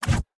Tab Select 12.wav